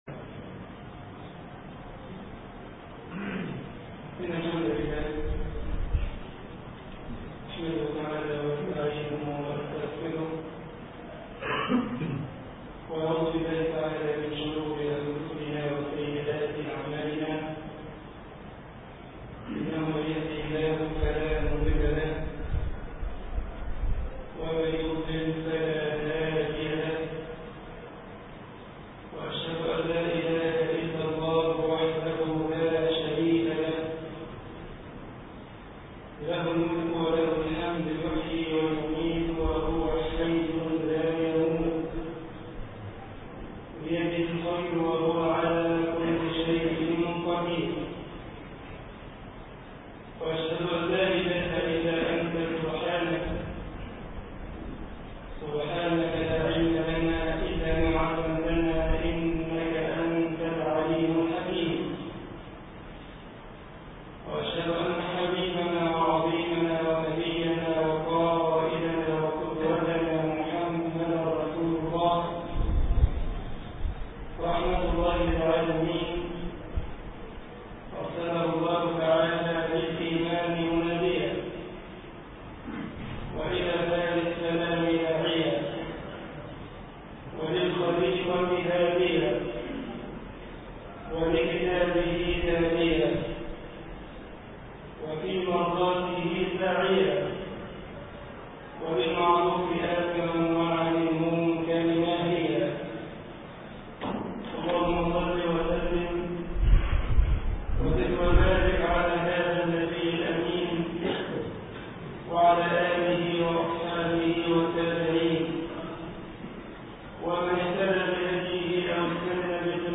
خطبة الجمعة
مسجد التوحيد ـ كتامة ـ طنطا